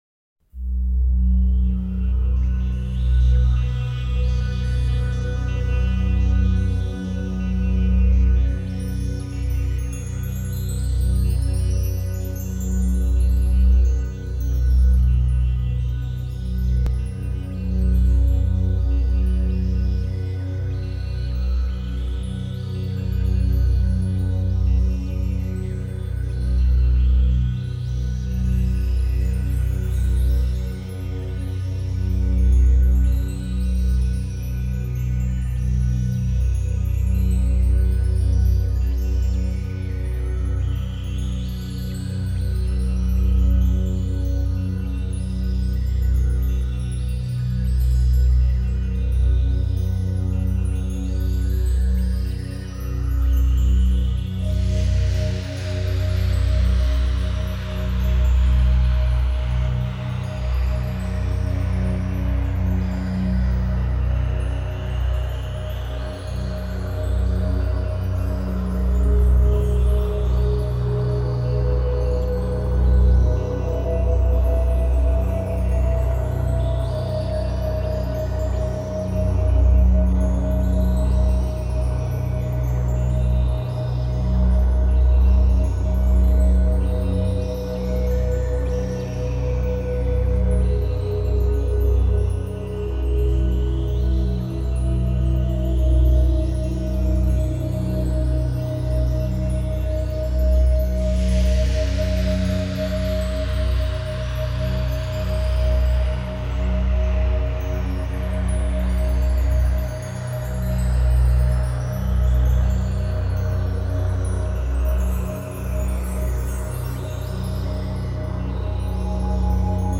随着历史叙述的起伏不断变换着节奏，低沉的打击乐仿佛敲响了永乐朝的大钟